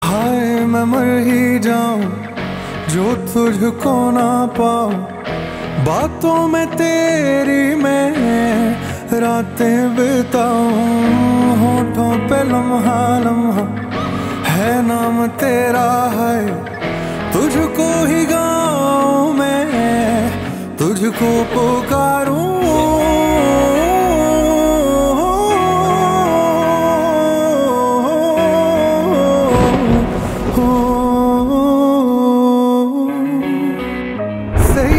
Set this emotional and soulful tune as your caller ringtone.